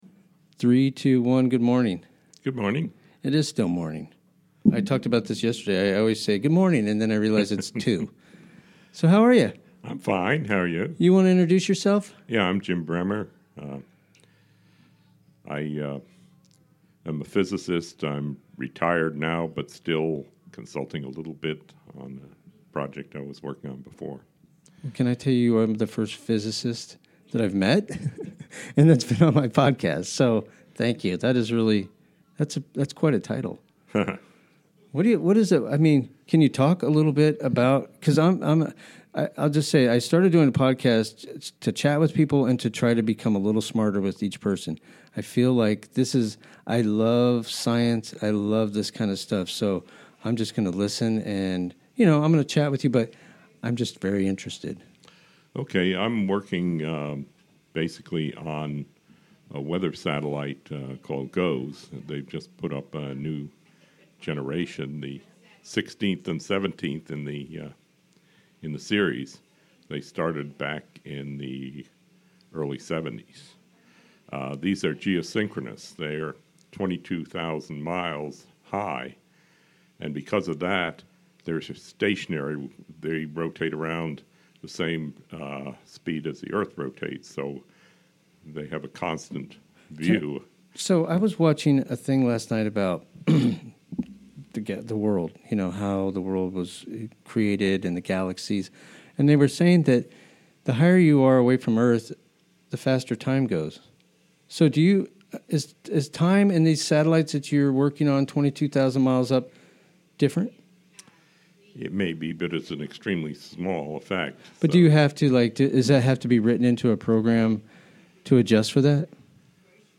Chatting with local folks.